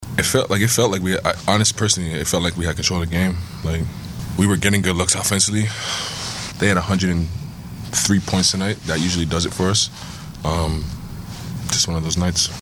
After the game, Shai Gilegous-Alexander said he felt like the game was there for the Thunder to grab.
SGA Postgame 1-26.mp3